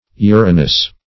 Search Result for " urinous" : The Collaborative International Dictionary of English v.0.48: Urinose \U"ri*nose\, Urinous \U"ri*nous\, a. [Cf. F. urineux.